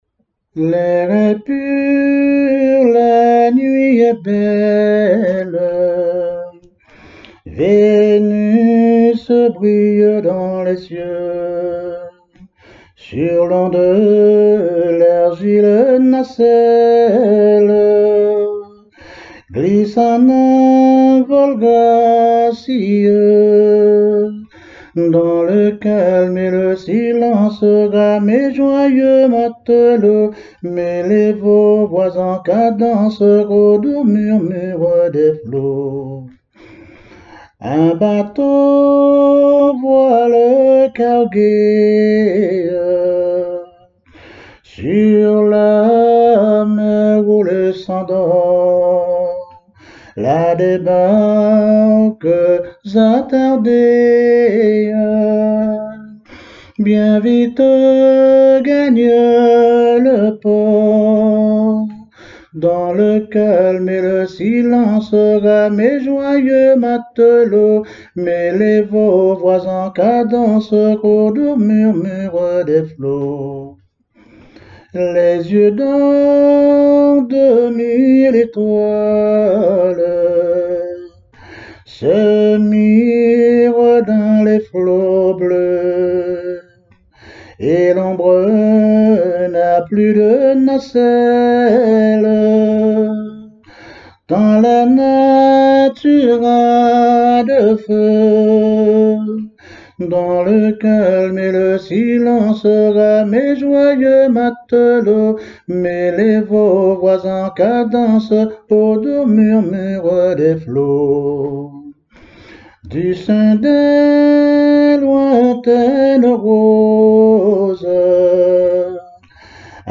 Genre strophique
chansons de traditions orales
Pièce musicale inédite